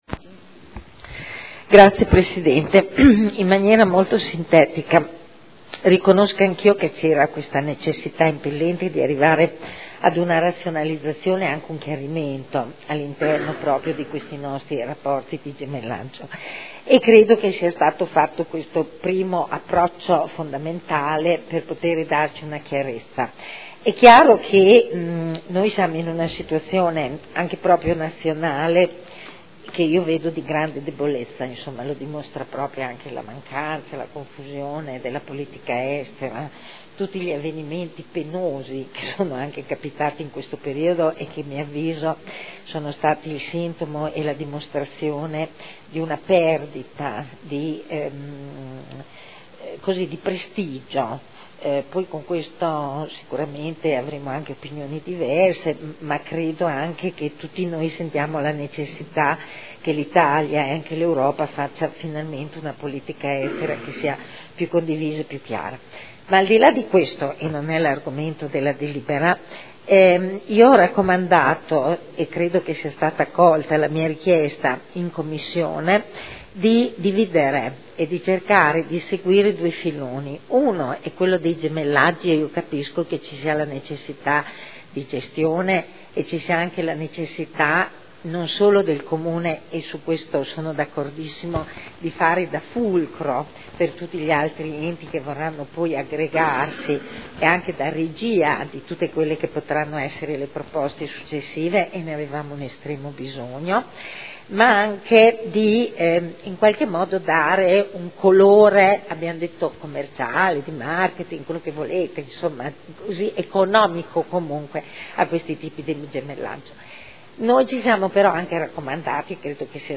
Seduta del 16 gennaio. Proposta di deliberazione: Regolamento per la costituzione, la gestione e lo sviluppo di relazioni internazionali con città, comunità e territori. Dibattito